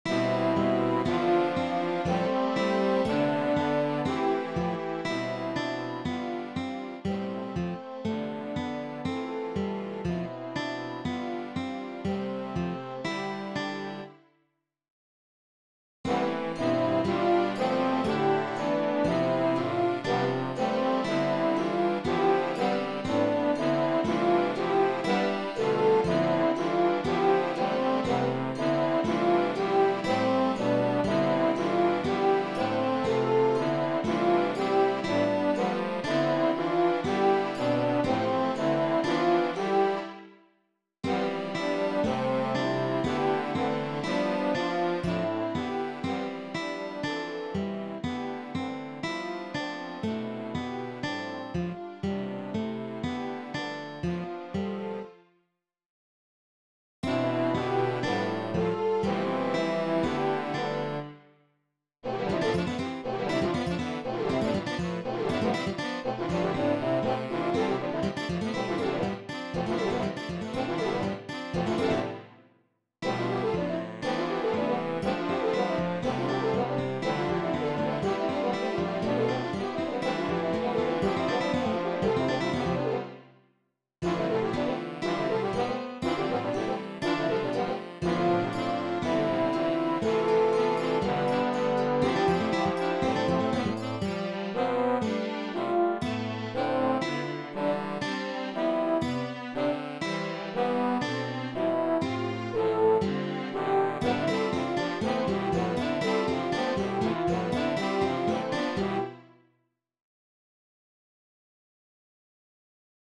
The Modern Symphony Music Prose Original Compostion.